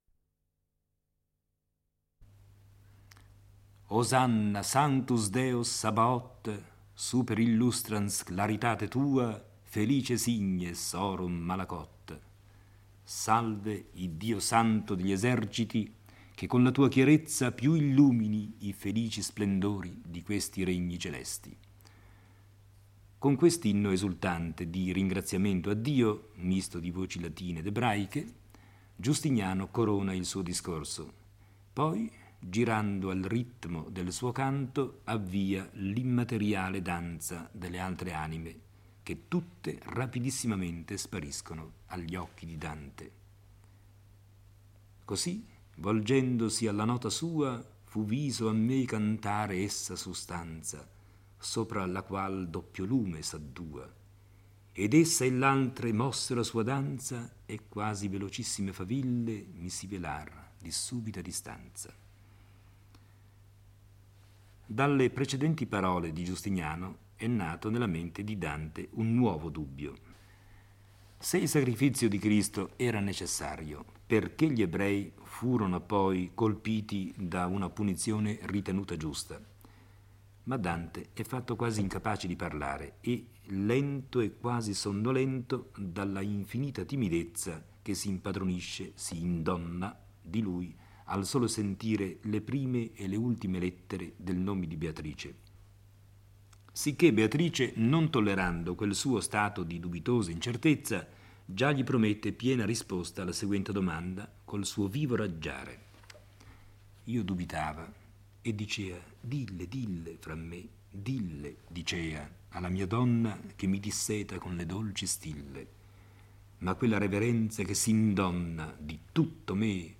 legge e commenta il VII canto del Paradiso.